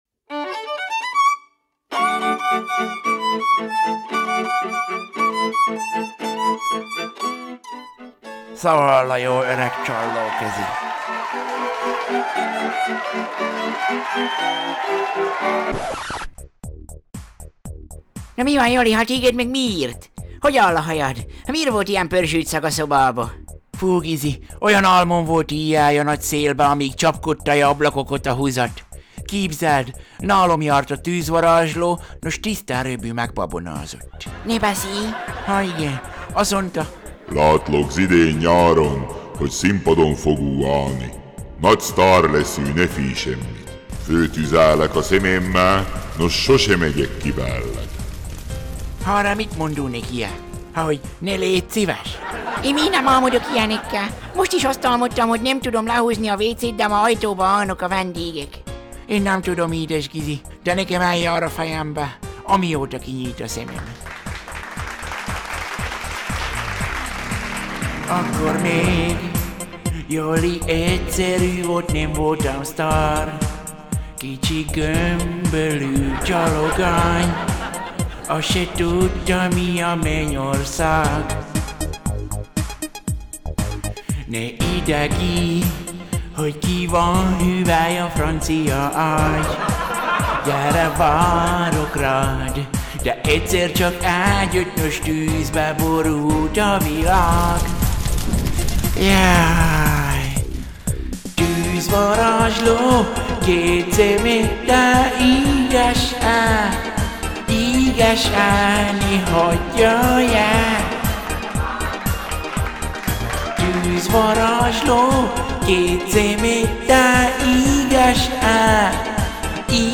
Az éjjel tapasztalt emlékeket egy dalban foglalja össze.